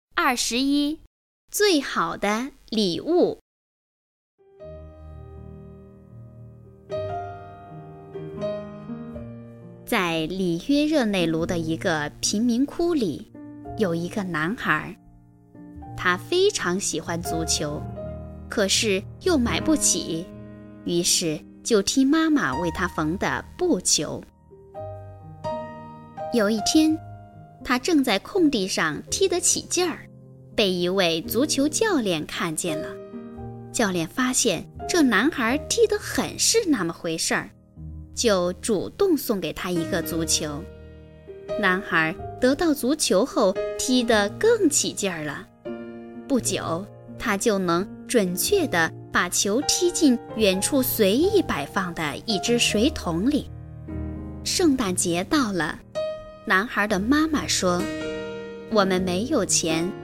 语文三年级上西师版21《最好的礼物》课文朗读_21世纪教育网-二一教育